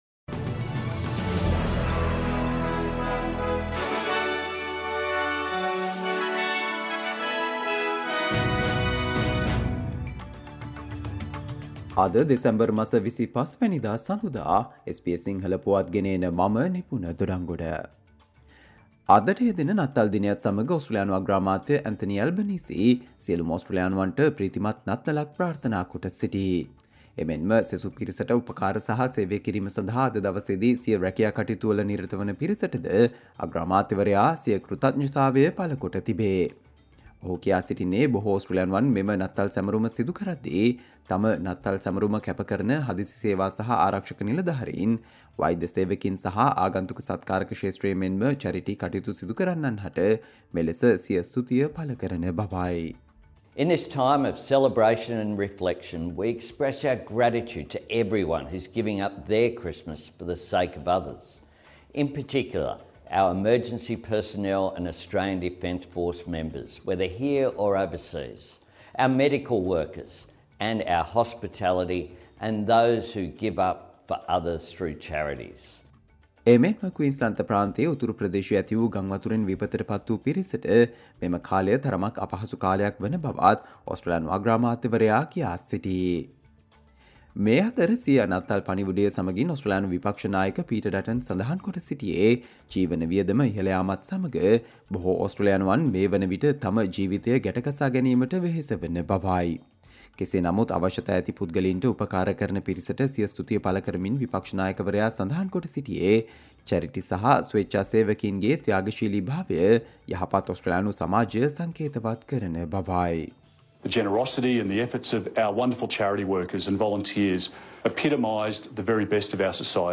Australia news in Sinhala, foreign and sports news in brief - listen, Thursday 25 December 2023 SBS Sinhala Radio News Flash